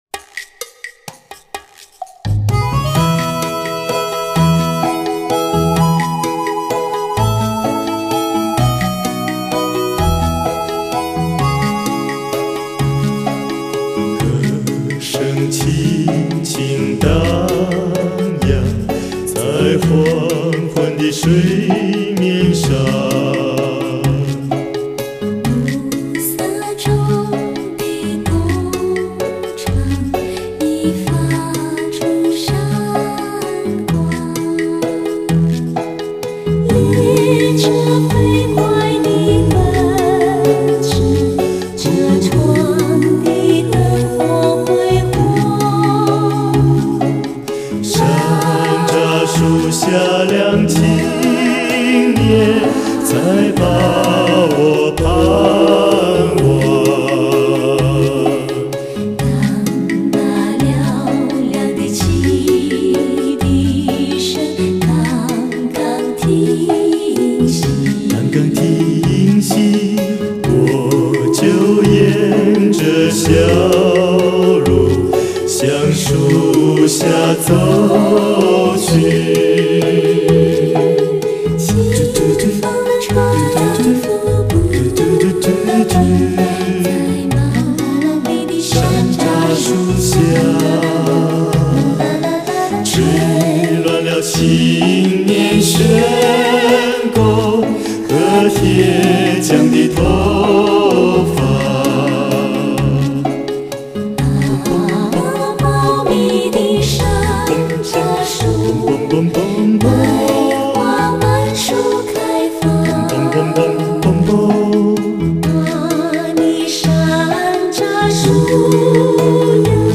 甜美悠揚的嗓音和極為專業的和音水準。
一組用至純至美的溫馨浸透您心靈的男女聲閤唱集。
錄音機：TASCAMA-80 24軌糢擬錄音機
MIC:U-87 非常廣泛使用的一種電容麥尅風